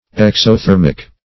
Exothermic \Ex`o*ther"mic\, a. [Pref. exo- + thermic.]